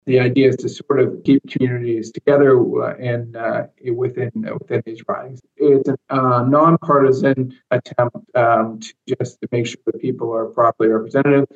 MP Philip Lawrence explains.